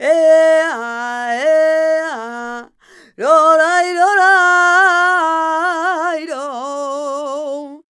46b19voc-d#.aif